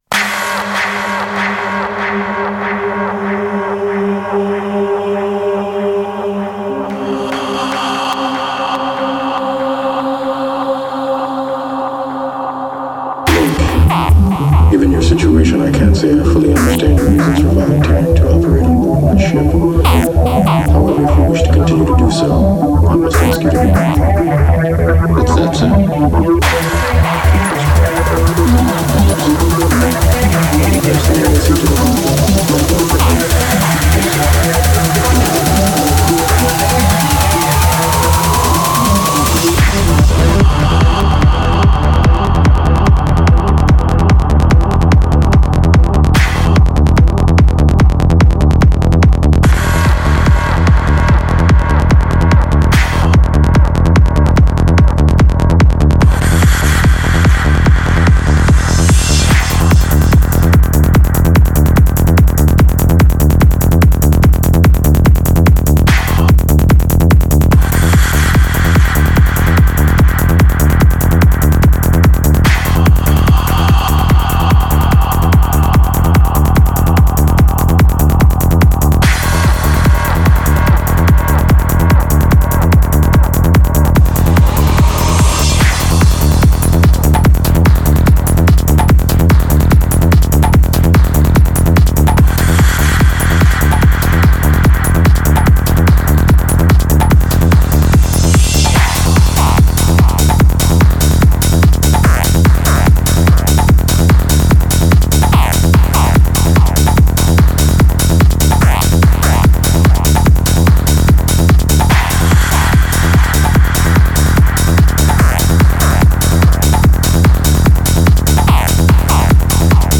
exkurze do historie hip-hopu